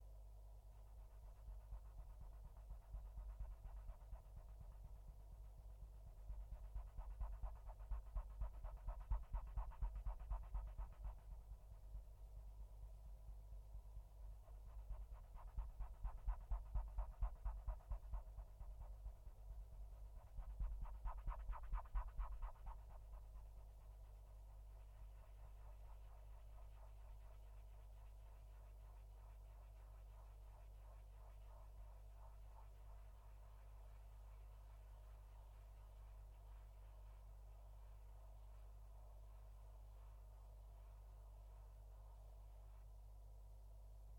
Test record - contact mic at min levels
On desk, rubbing fingers against desk